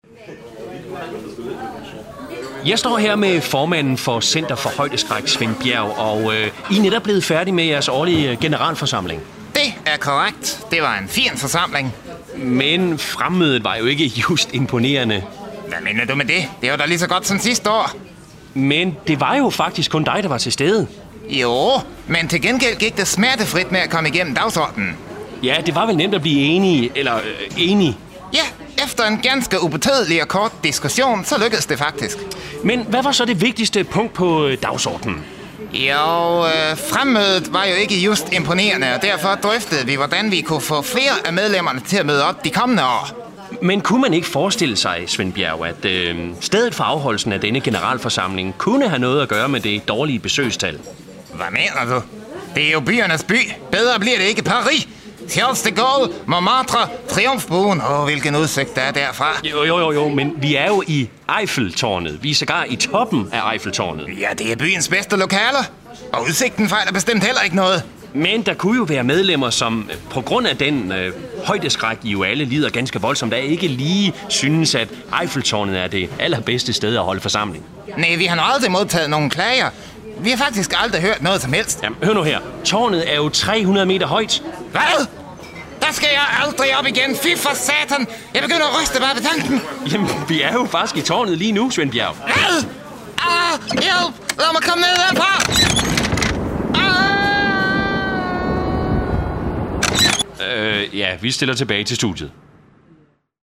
Det er her, du kan høre alle de gode, gamle indslag fra ANR's legendariske satireprogram.
For anden gang i Farlig Fredags historie blev redaktionen samlet til 3 timers "Farligt Nytår" nytårsaftensdag.